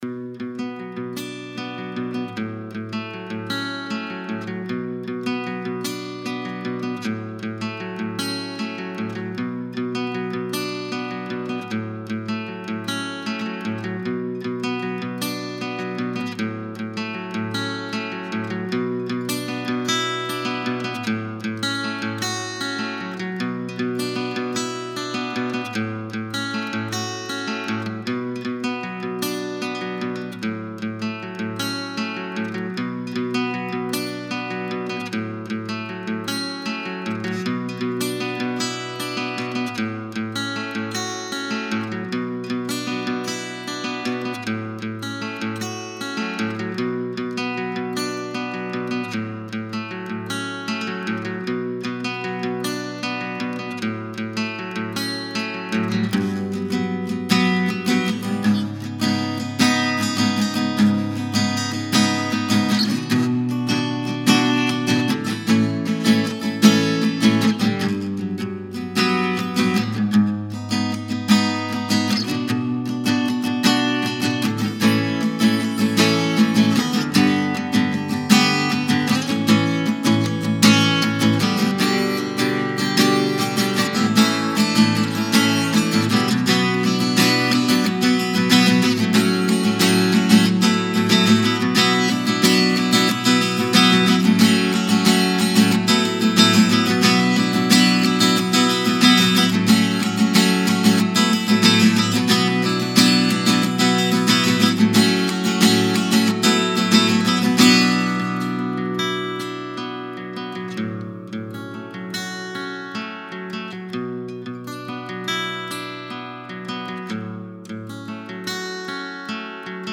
Neuer Song ohne Vocals